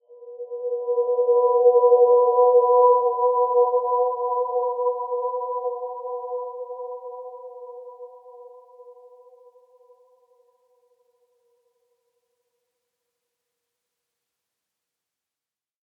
healing-soundscapes
Dreamy-Fifths-B4-mf.wav